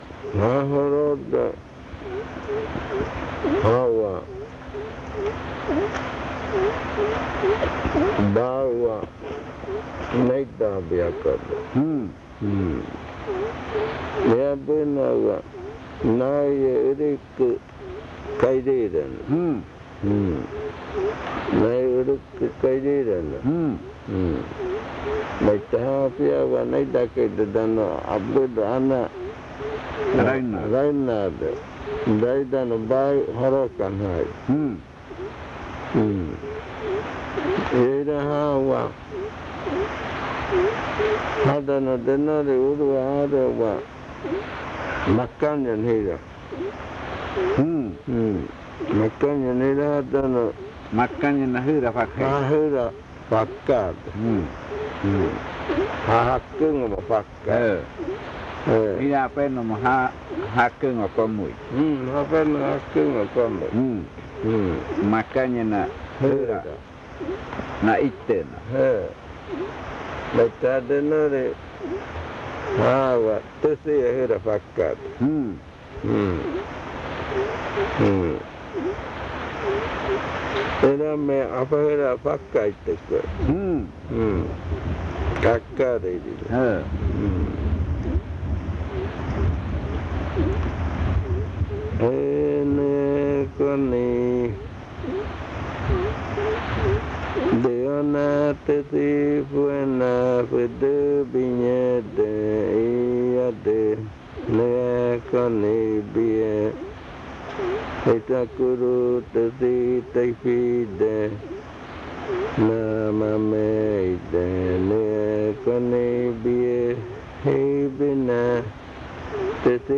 Adofikɨ (Cordillera), río Igaraparaná, Amazonas